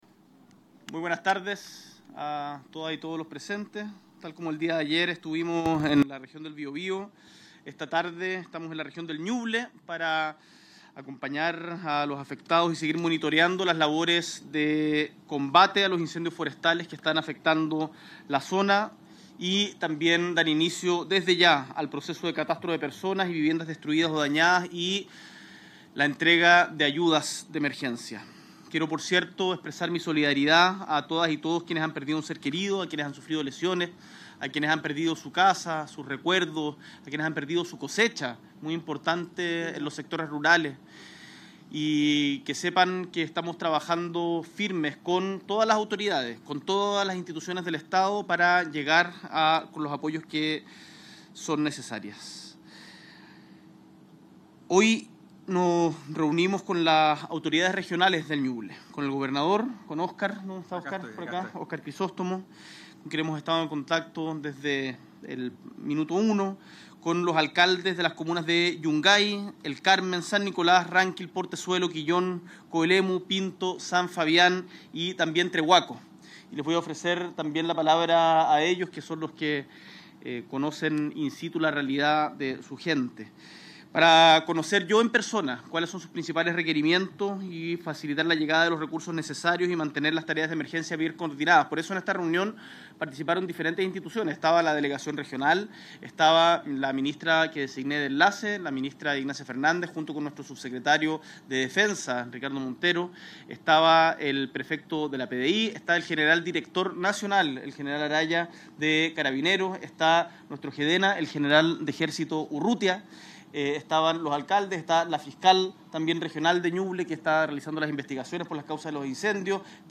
Punto-Prensa-Nuble-19-ene.mp3